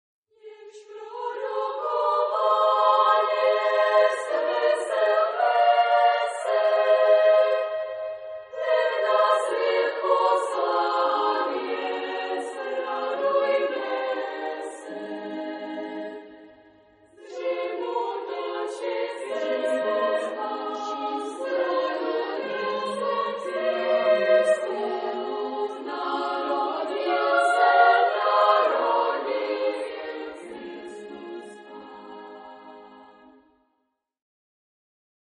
Genre-Style-Form: Christmas carol
Mood of the piece: allegretto
Type of Choir: SSAA  (4 children OR women voices )
Tonality: A flat major